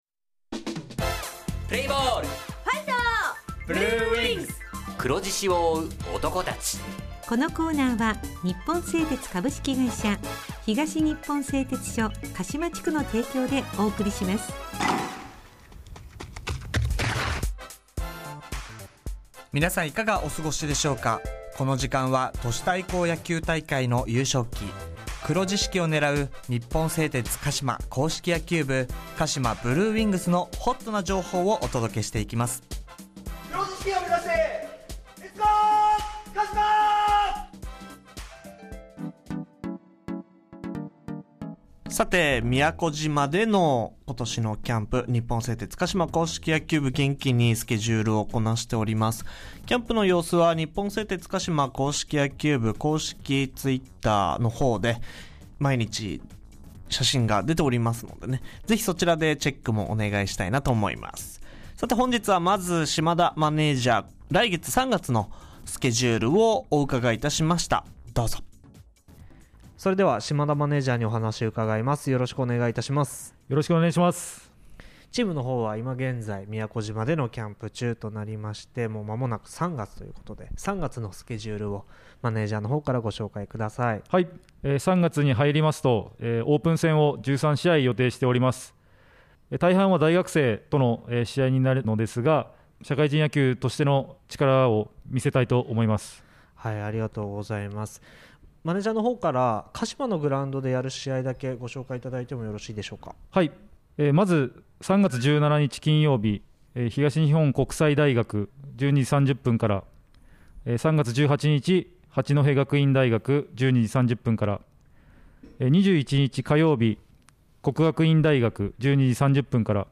地元ＦＭ放送局「エフエムかしま」にて当所硬式野球部の番組放送しています。